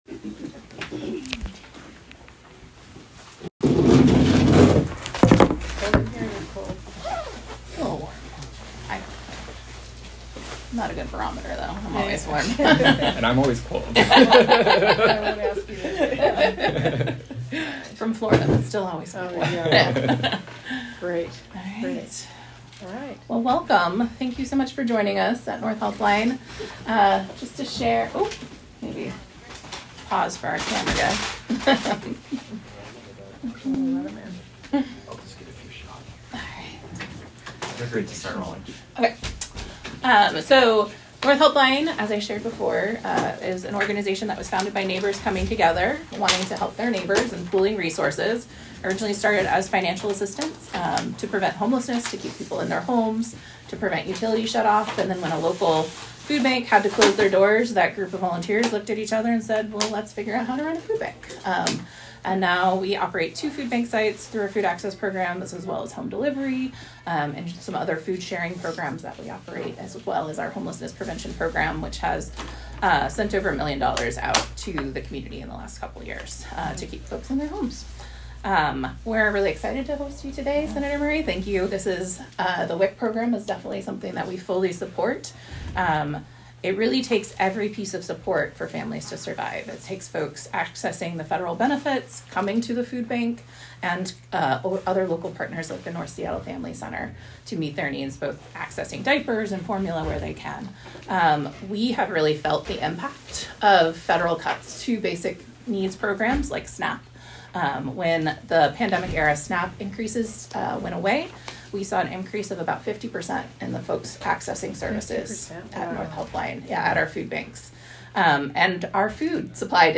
Senator Murray Hosts Roundtable with Local Nutrition Advocates, Officials, Moms; Reiterates her Commitment to Fully Funding WIC for Moms & Babies As Program Faces Shortfall - Senator Patty Murray